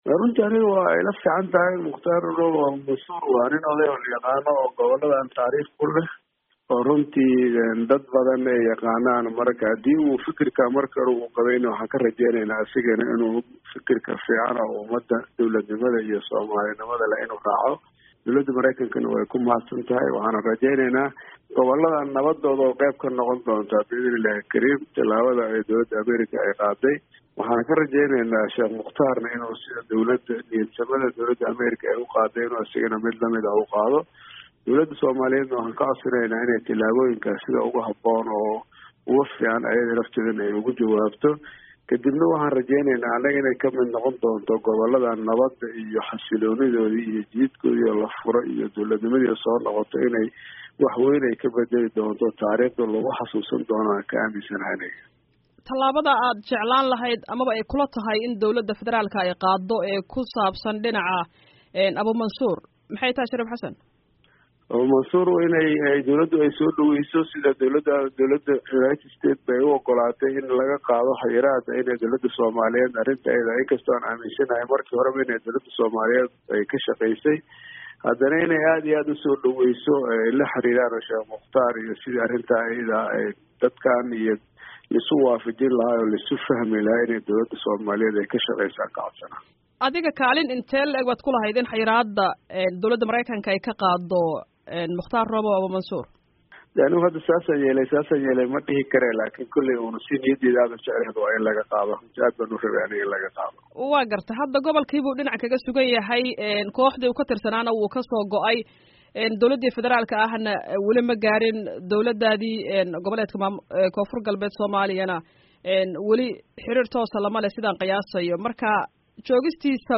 Wareysi: Shariif Xasan Sheekh Aadan